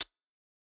UHH_ElectroHatA_Hit-17.wav